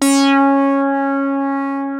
OSCAR 8 C#5.wav